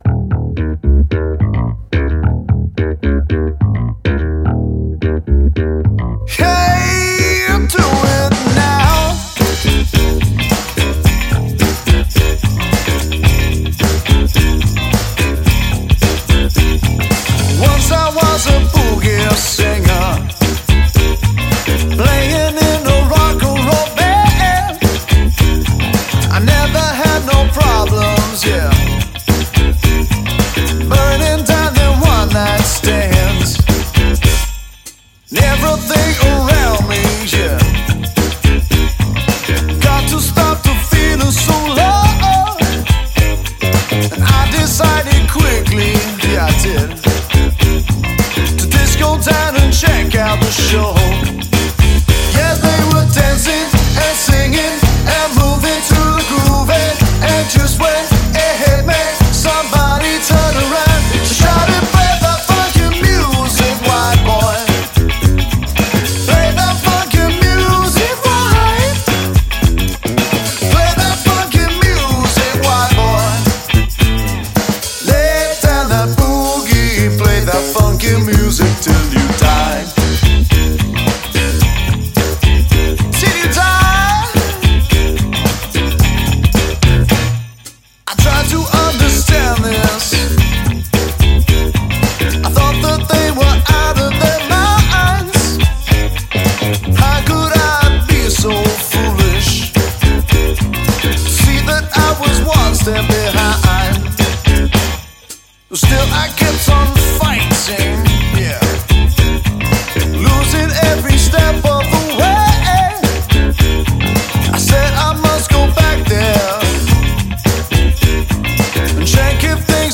function band